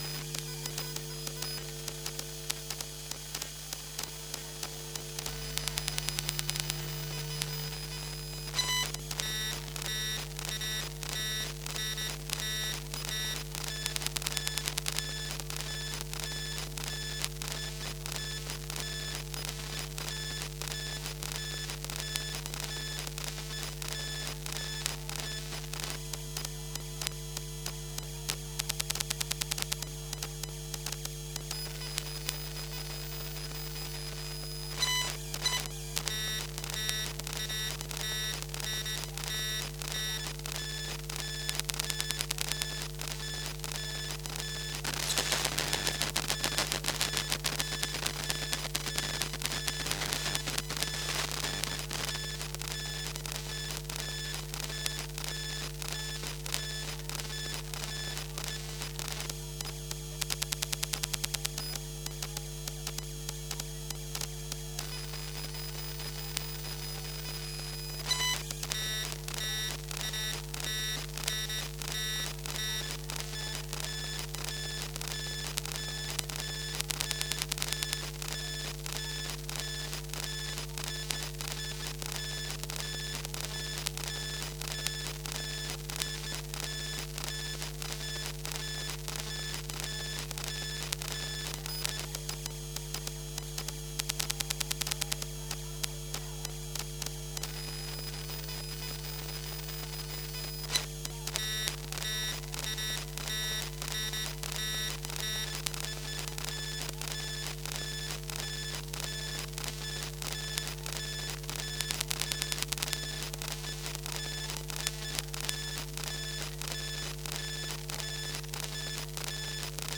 So this is what a phone does when left alone?